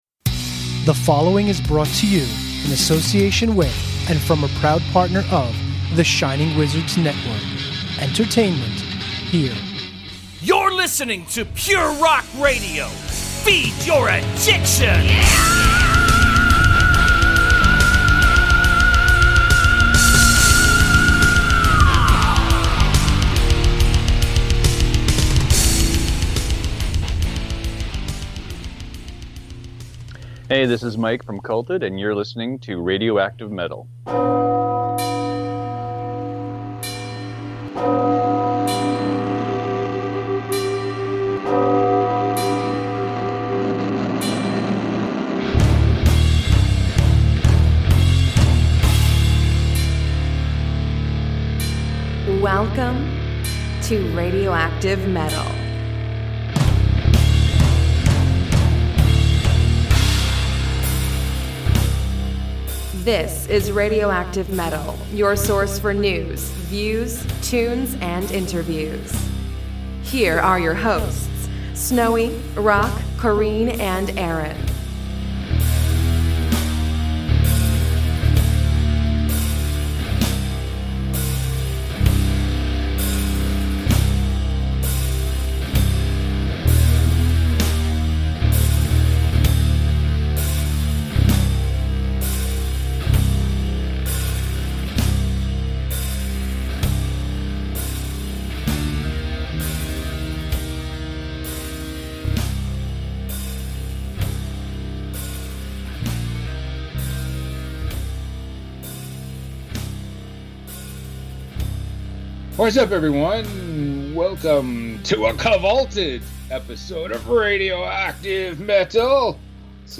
Radioactive Metal 646: Kvlted!! – interview with Culted